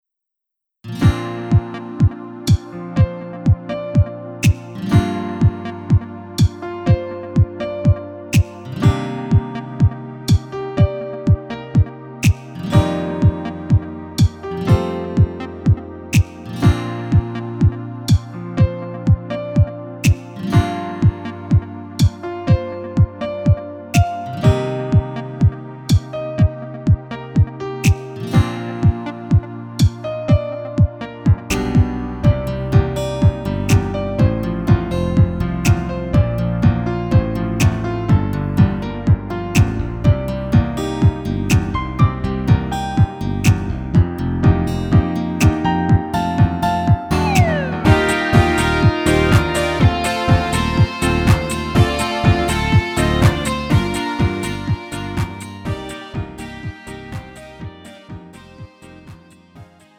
음정 -1키 3:17
장르 가요 구분 Lite MR
Lite MR은 저렴한 가격에 간단한 연습이나 취미용으로 활용할 수 있는 가벼운 반주입니다.